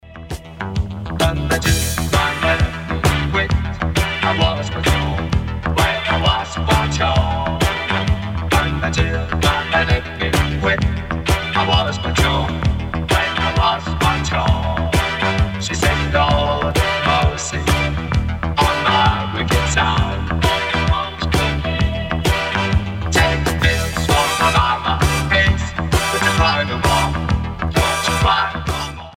facevano uso massiccio di elettronica e sintetizzatori